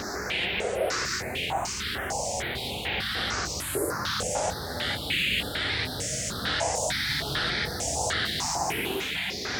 STK_MovingNoiseD-100_03.wav